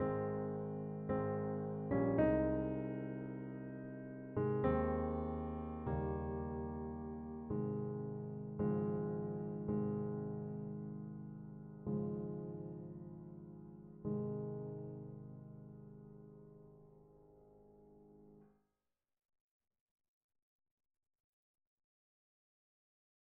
Piano Sonata no.2 in A-flat Major, 1st Movement - Piano Music, Solo Keyboard